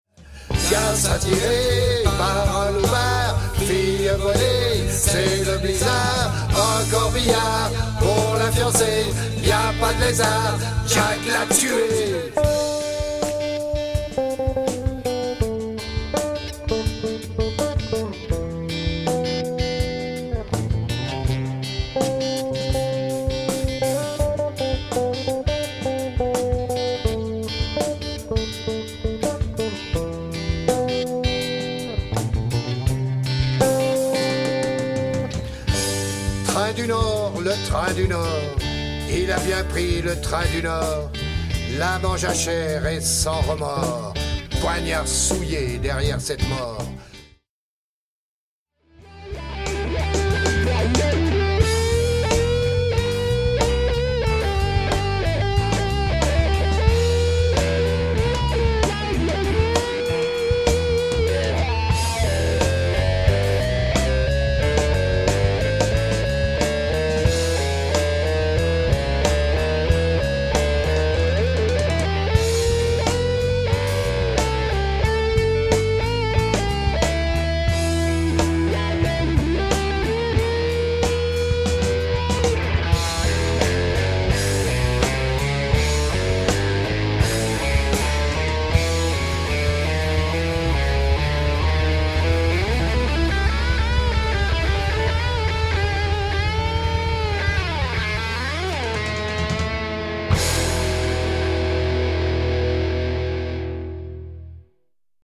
Basse
Studio Live 1995